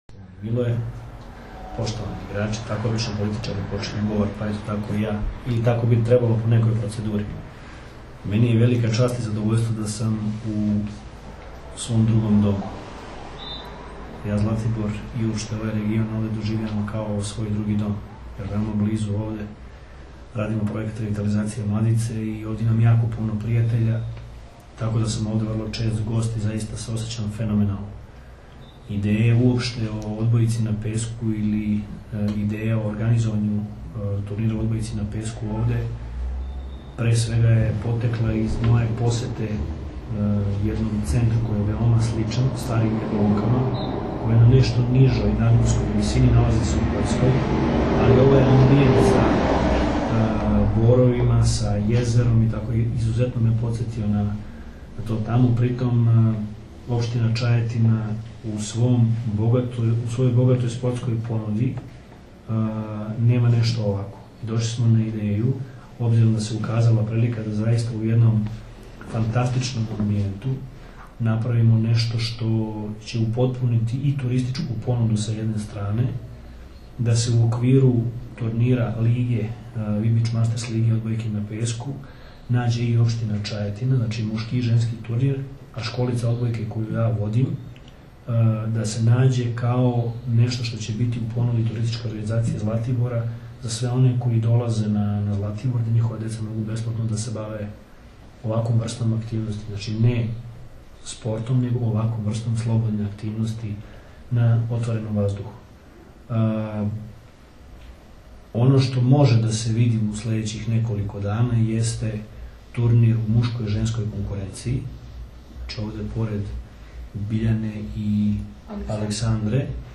Na Zlatiboru startovala “Kids liga” i “Vip Beach Masters škole odbojke” – održana konferencija za novinare
IZJAVA VLADIMIRA GRBIĆA